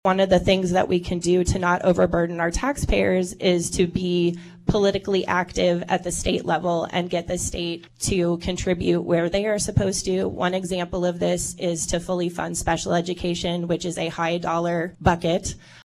Rising property taxes was a point of emphasis at the USD 383 candidate forum, hosted by the League of Women Voters Saturday.